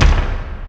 Blast.wav